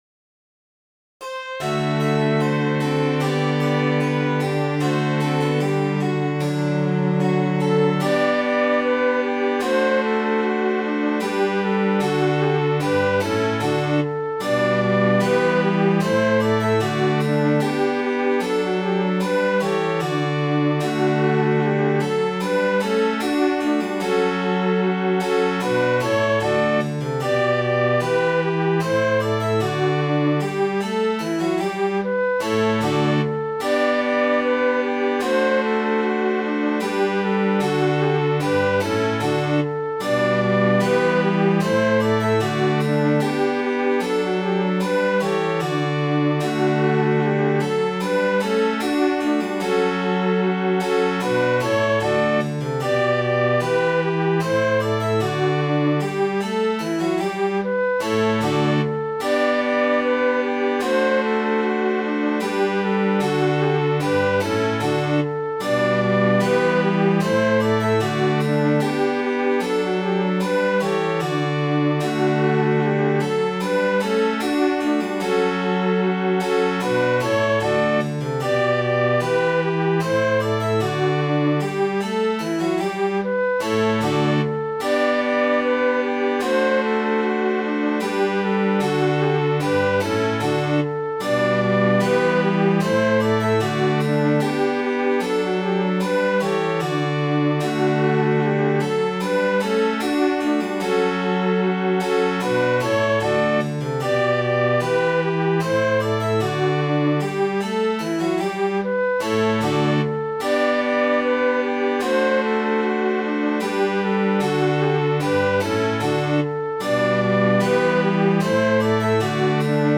Midi File, Lyrics and Information to Rosetta and Her Gay Ploughboy